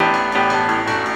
keys_16.wav